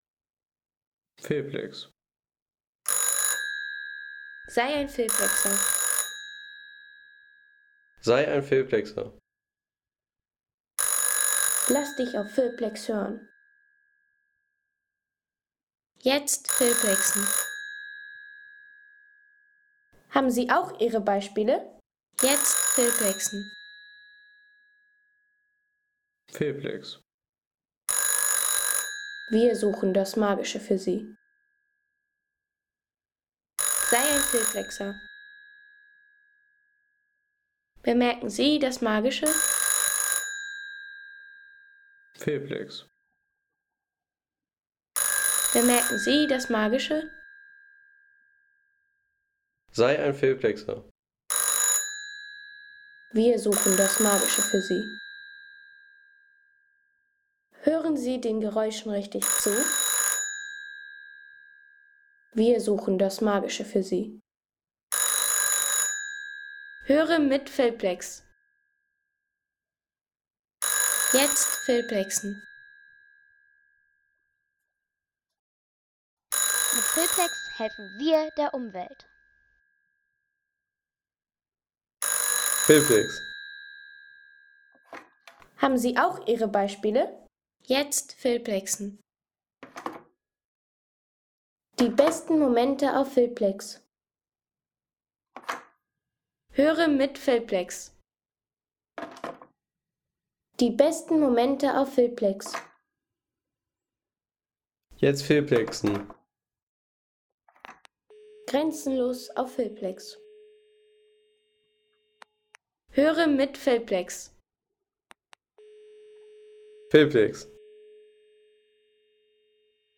Telefontöne FeTAp 611-2
Fernsprechtischapparat FeTAp 611-2 – Deutsche Bundespost Der Fernsp ... 3,50 € Inkl. 19% MwSt.